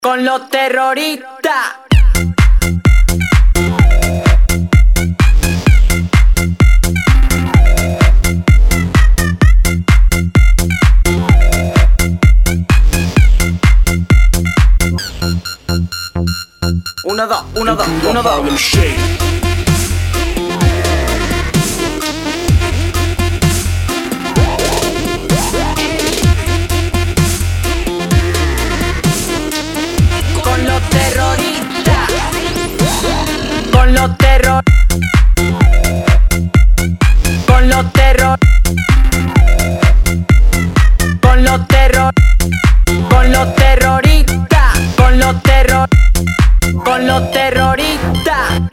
• Качество: 192, Stereo
dance